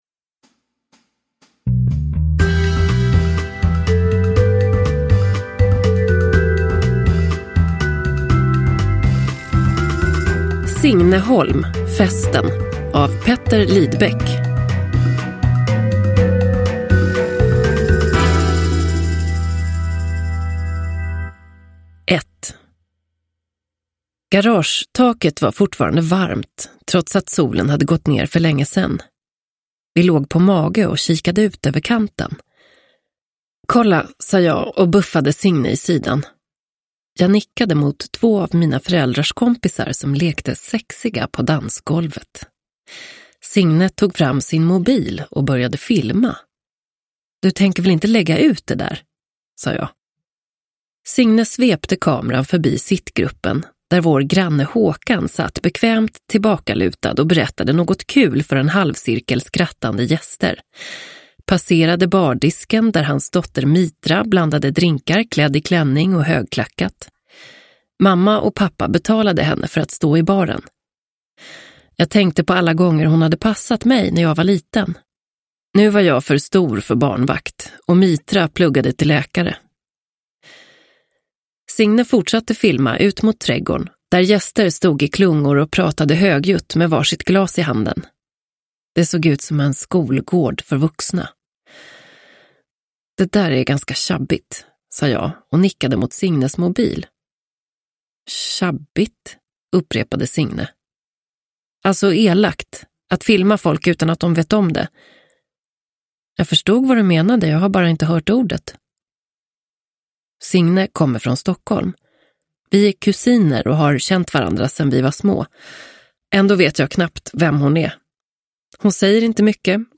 Festen – Ljudbok